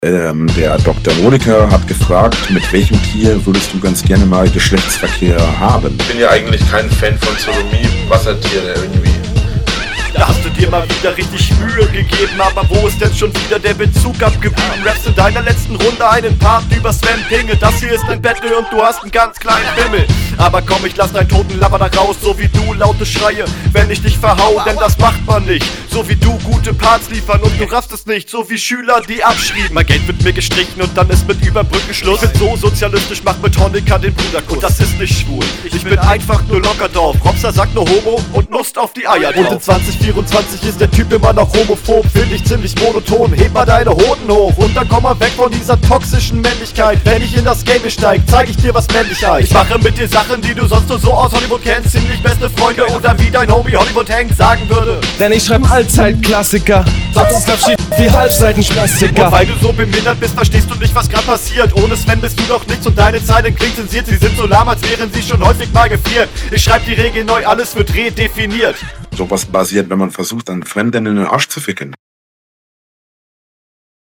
Finde flowlich ziehst du ganz gut mit bleibst aber noch leicht hinter deinem Gegner, textlich …